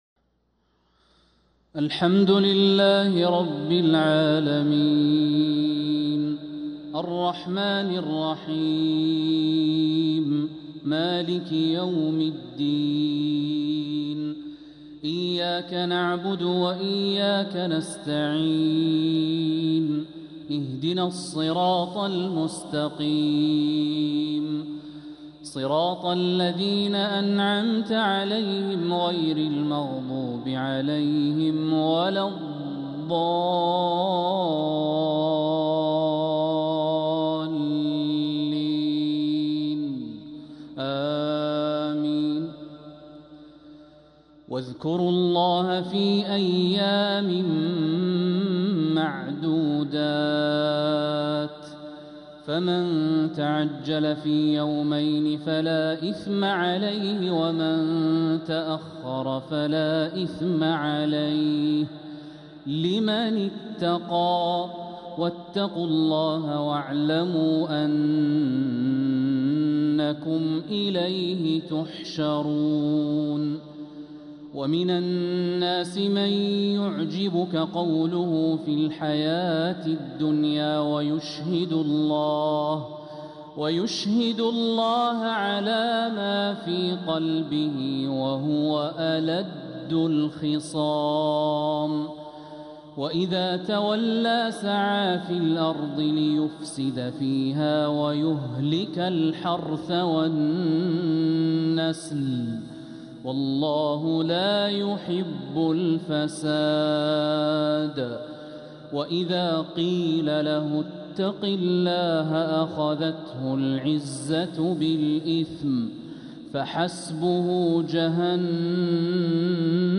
عشاء الجمعة 4 ذو القعدة 1446هـ من سورة البقرة 203-210 | Isha prayer from Surah Al-Baqarah 2-5-2025 > 1446 🕋 > الفروض - تلاوات الحرمين